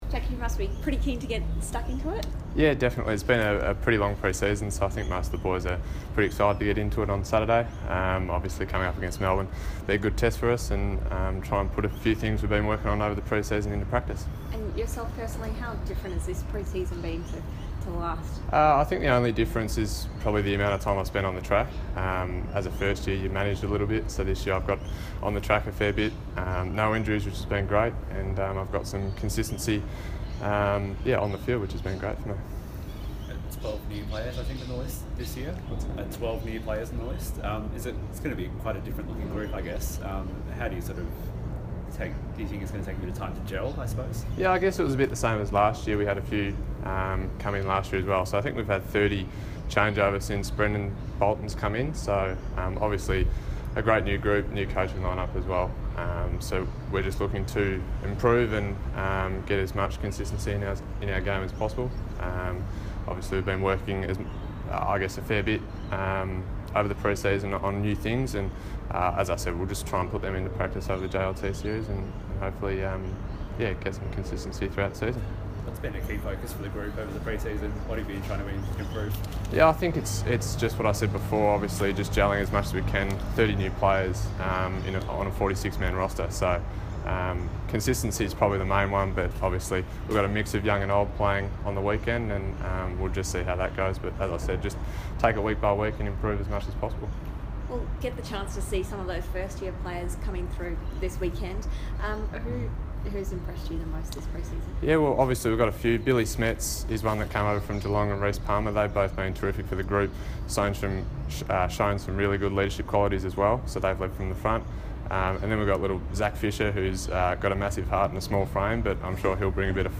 Jacob Weitering press conference - February 24
Carlton defender Jacob Weitering speaks to the media ahead of the Blues' first JLT Community Series match.